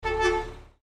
Звуки мусоровоза
Звук двух гудков мусоровоза